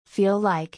/-l/ ＋ /l-/ feel_like[fíːl_ lάɪk]「フィールライク→フィーライク」